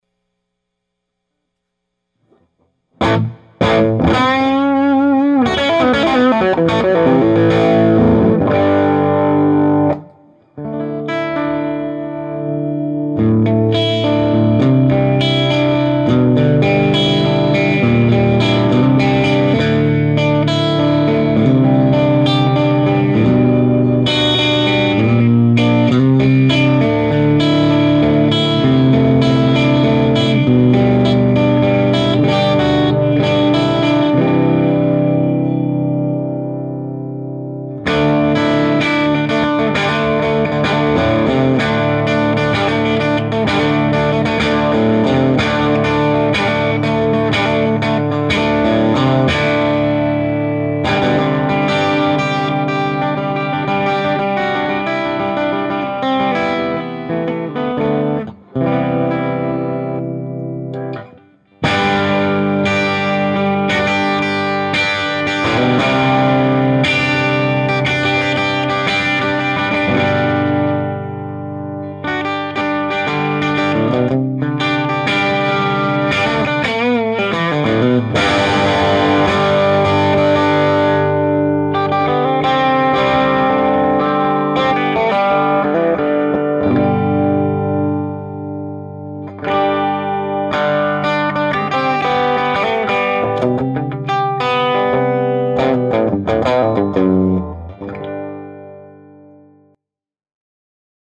miced with an SM-57 and a condenser. THe guitar is my les paul custom.
quackage as well. When you turn up the guitar you get nice rhythm
The clips are pretty much all the EF86 channel.
The more you turn up the master the more it starts to sing.
TC-15_2_lespaul.mp3